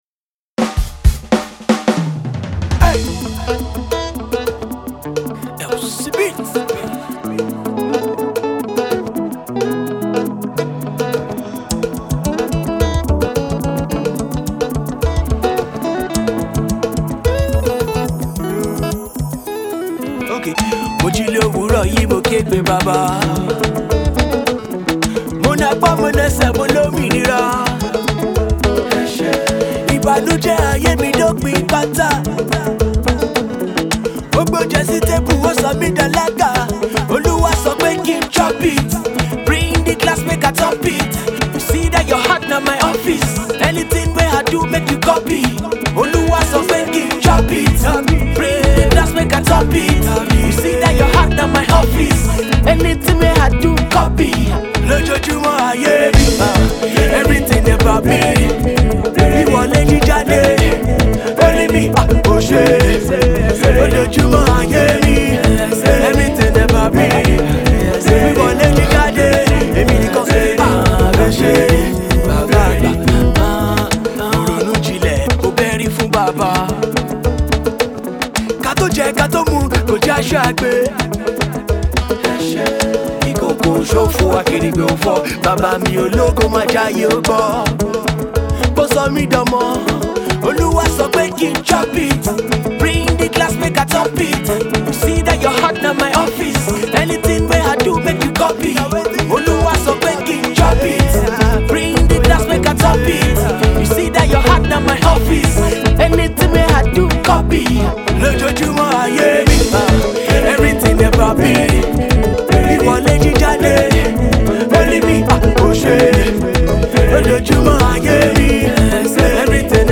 If you are a fan of Christian urban music